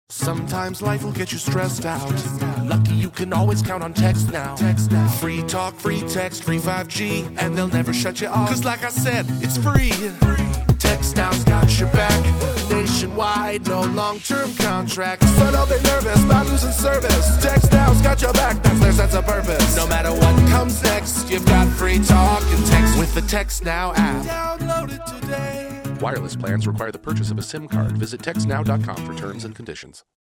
Audio Ads
Formats: Streaming Audio (Music + Non-Music Variants), Accompanying Banner Ads